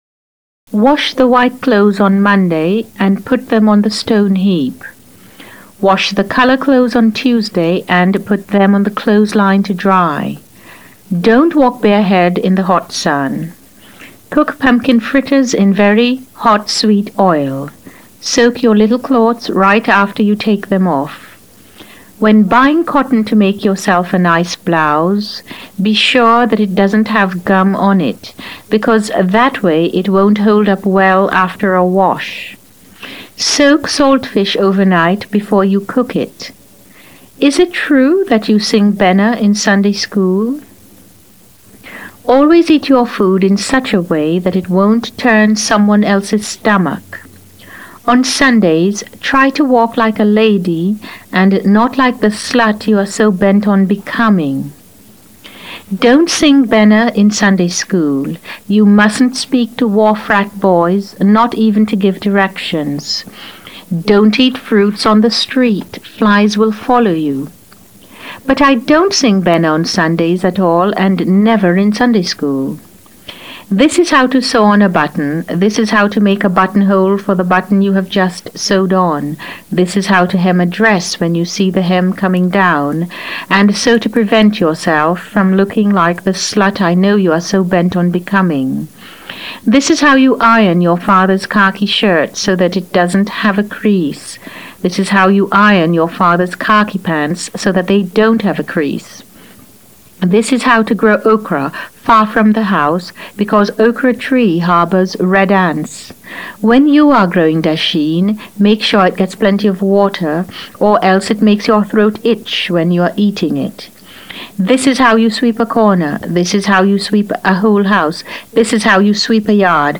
As you listen to “Girl,” notice the rhythms of the language, and consider how the almost poetic litany of instructions reflects and shapes the reader’s understanding of the relationship between mother and daughter.
Listen to Jamaica Kincaid reading “Girl.”